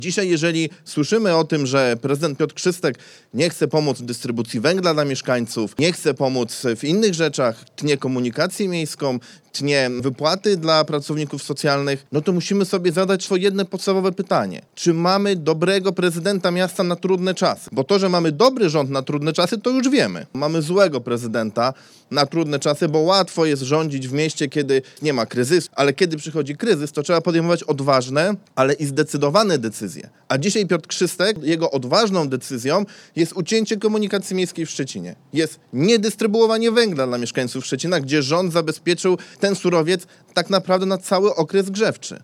Politycy Prawa i Sprawiedliwości podczas konferencji prasowej, krytycznie odnieśli się do działań prezydenta Piotra Krzystka. Chodzi o brak działań w zakresie dystrybucji węgla.
W podobnym tonie wypowiada się radny miejski Krzysztof Romianowski